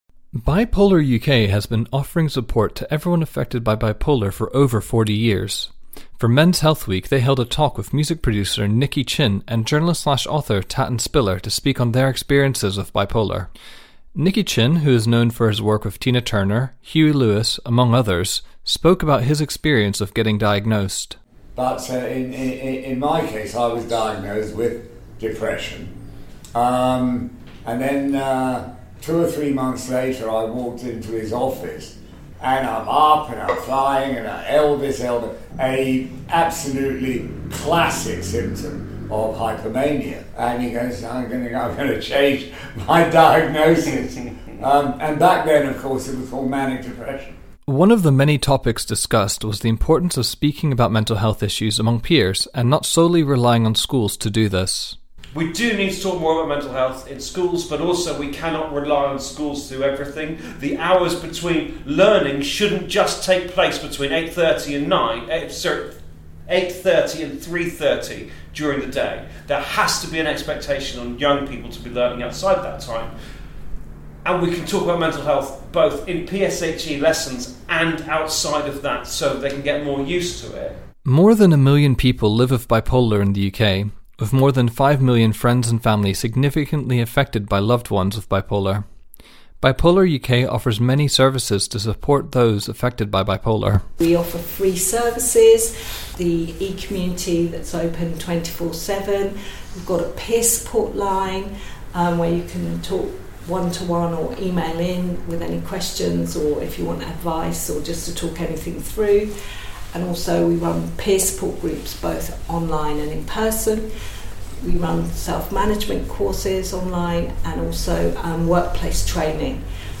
special report on recognising Bipolar disorder.